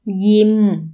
– yimm